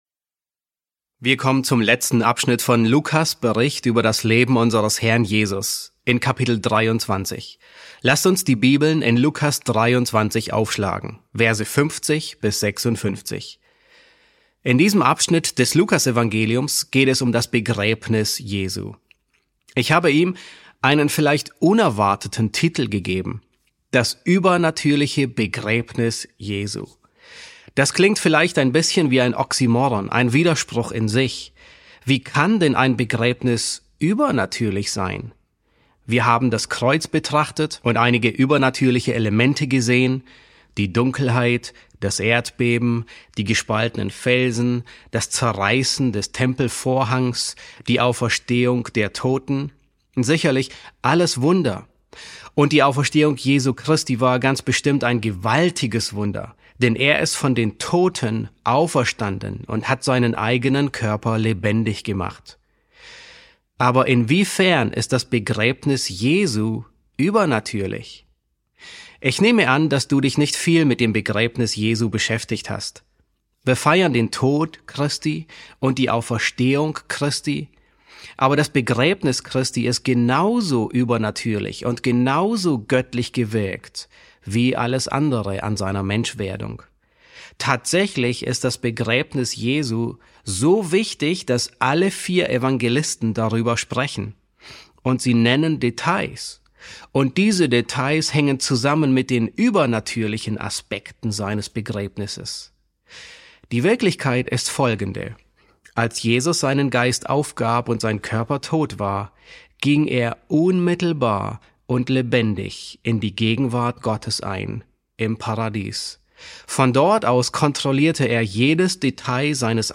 E1 S2 | Das übernatürliche Begräbnis Christi ~ John MacArthur Predigten auf Deutsch Podcast